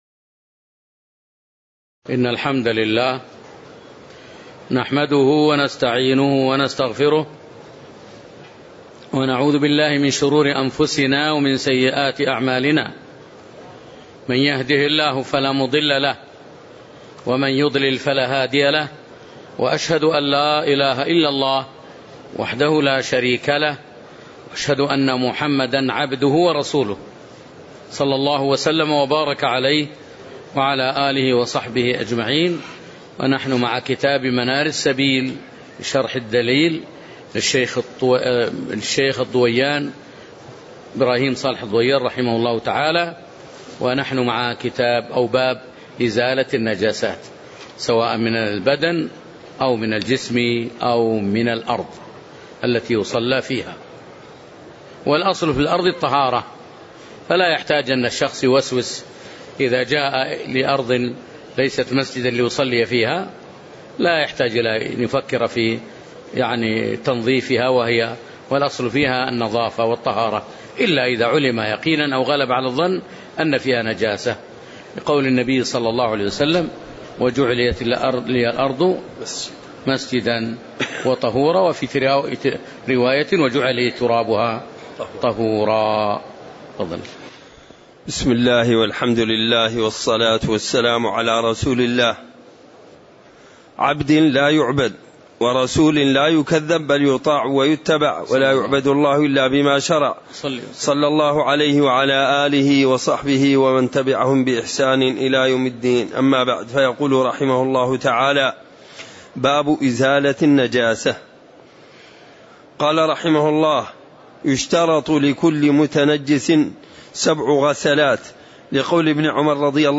تاريخ النشر ٧ شعبان ١٤٣٨ هـ المكان: المسجد النبوي الشيخ